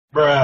Download Bruh Sound sound effect for free.
Bruh Sound